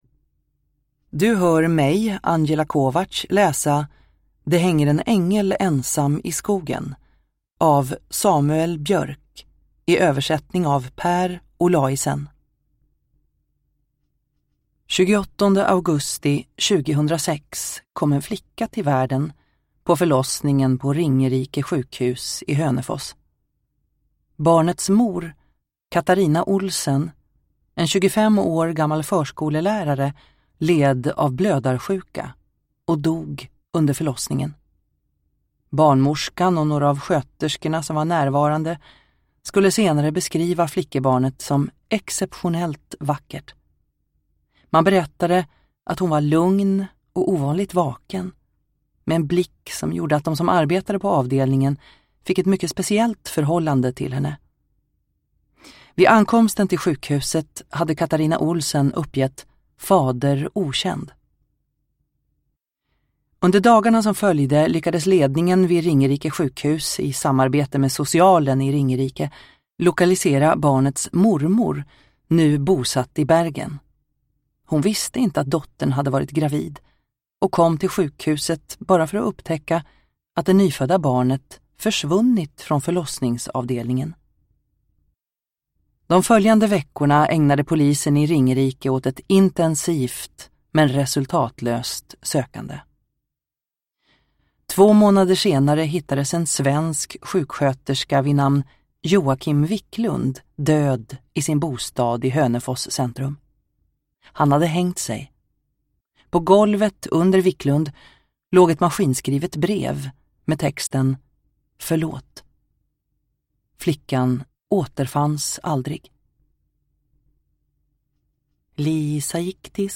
Det hänger en ängel ensam i skogen – Ljudbok – Laddas ner